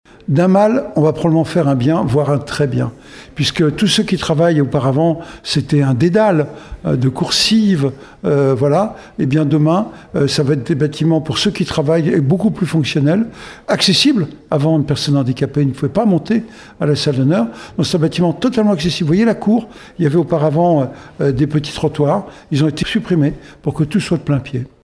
On écoute le maire Jean-François Fountaine :